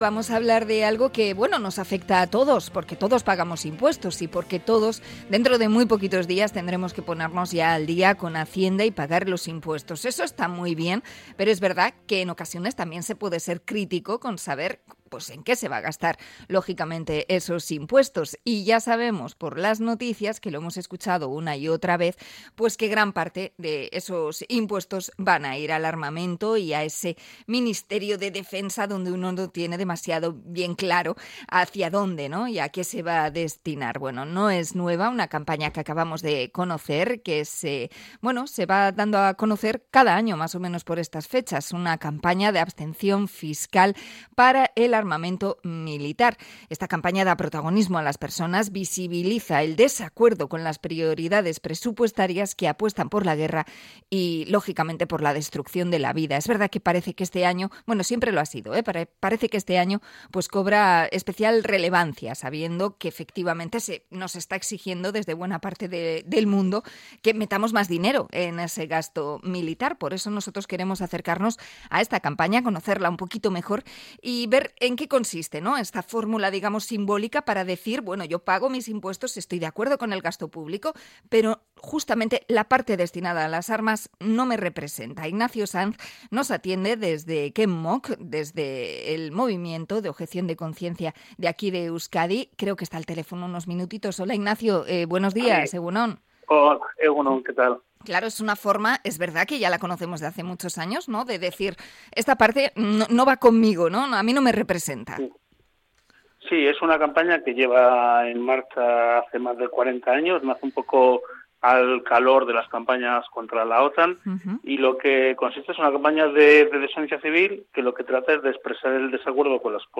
Entrevista al movimiento antimilitarista sobre el gasto militar